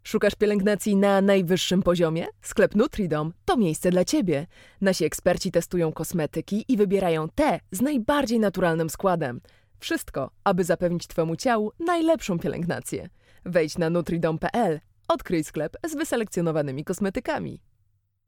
Female 30-50 lat
From an energetic and cheerful girl, through a cool professional, to a seductive femme fatale.
Spot reklamowy